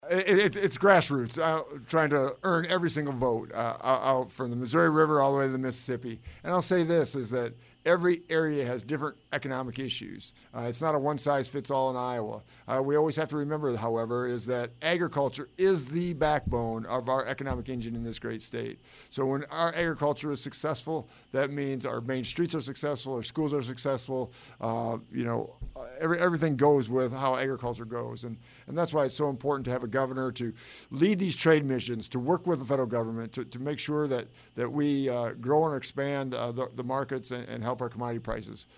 Congressman Randy Feenstra stopped by KLEM yesterday afternoon to discuss his reaction to President Trump’s State of the Union address, as well as his campaign for governor of Iowa.